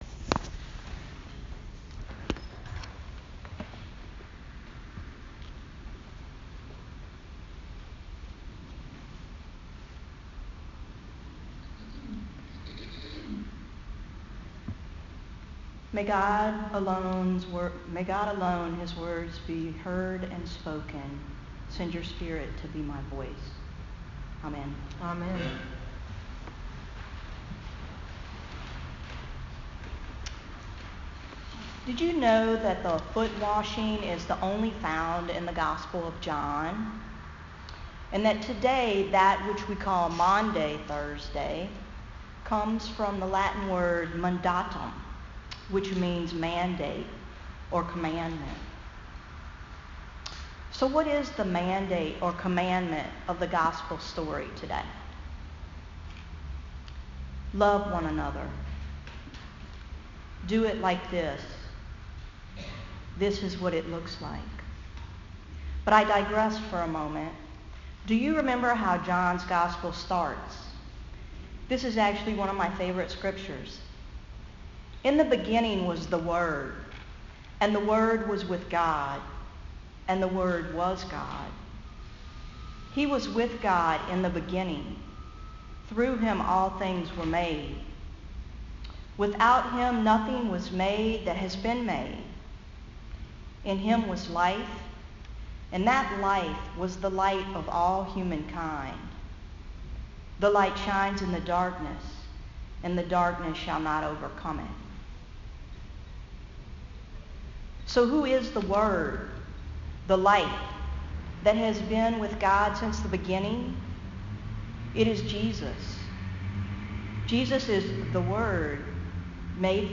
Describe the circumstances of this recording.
Rec-001-Maundy_Thursday.m4a